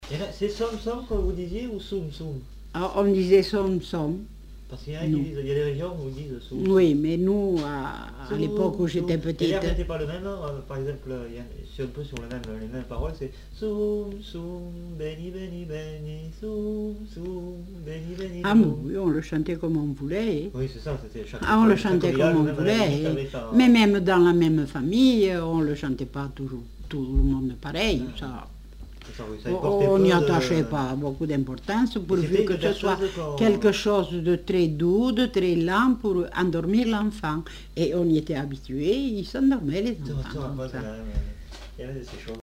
Lieu : Montauban-de-Luchon
Genre : témoignage thématique